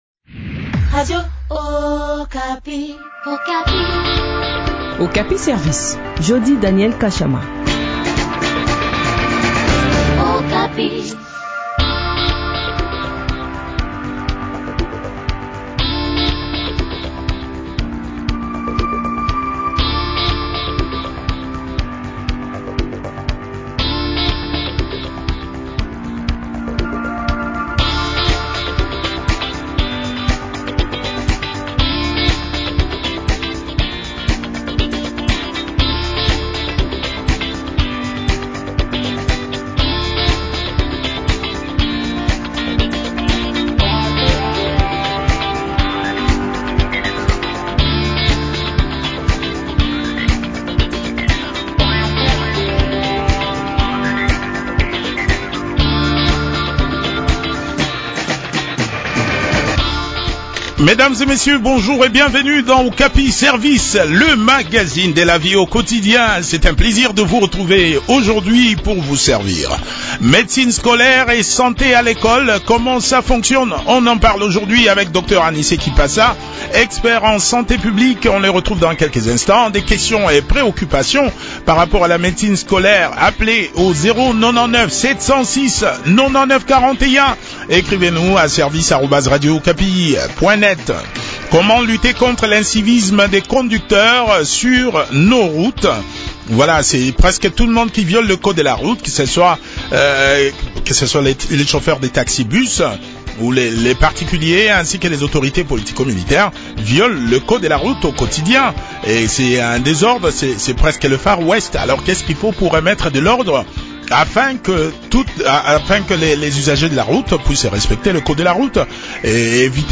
expert en santé publique.